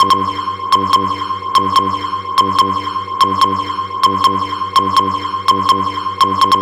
Lead 145-BPM G.wav